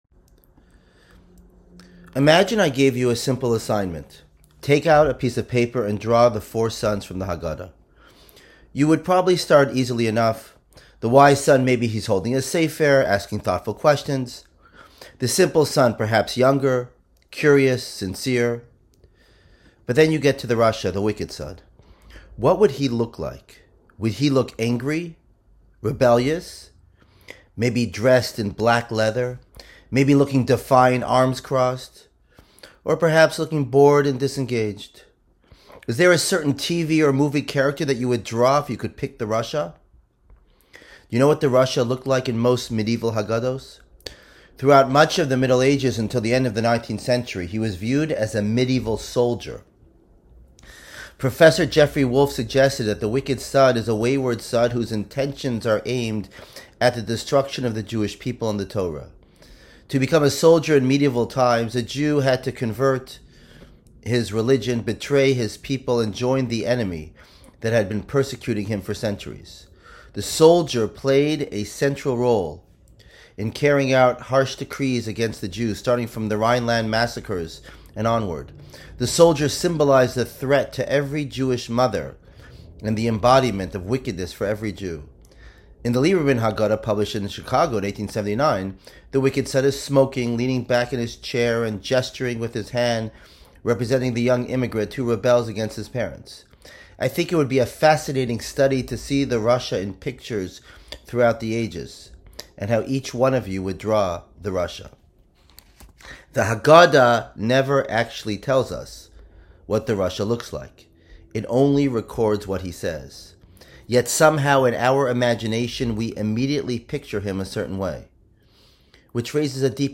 shabbat hagadol drasha_72_.m4a